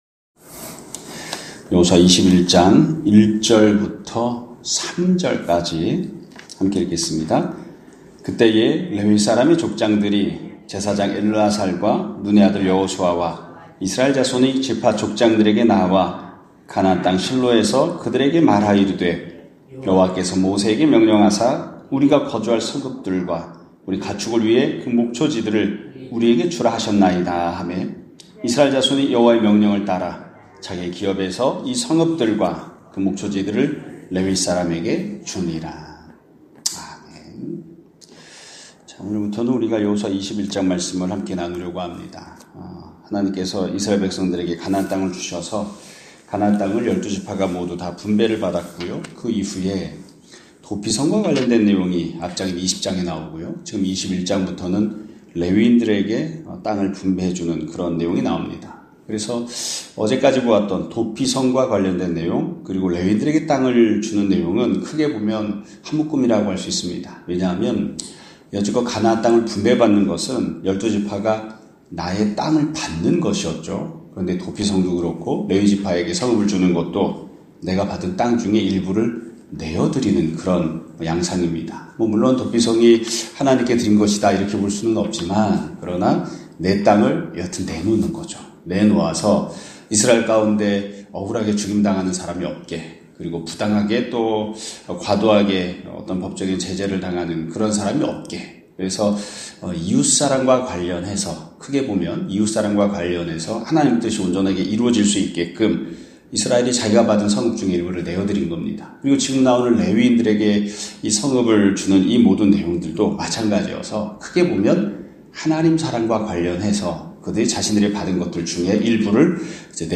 2025년 1월 17일(금요일) <아침예배> 설교입니다.